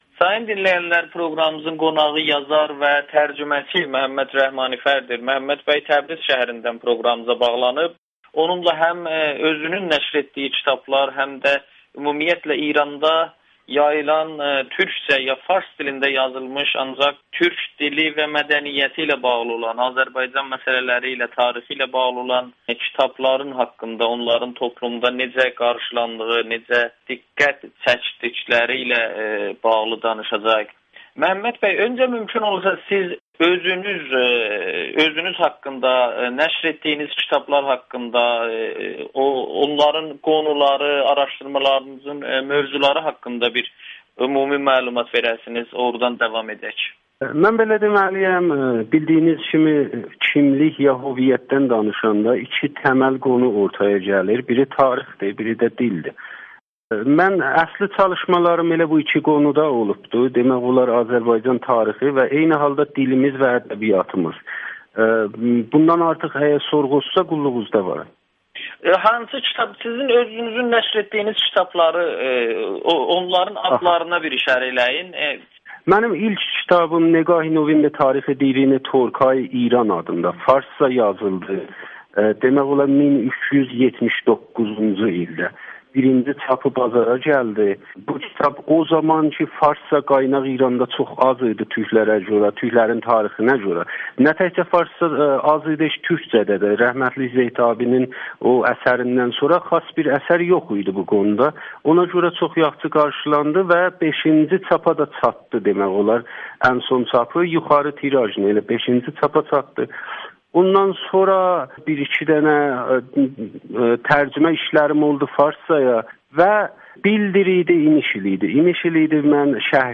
Kitablarımın mövzusu dilimiz və tariximizdir [Audio-Müsahibə]